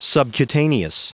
Prononciation du mot subcutaneous en anglais (fichier audio)
Prononciation du mot : subcutaneous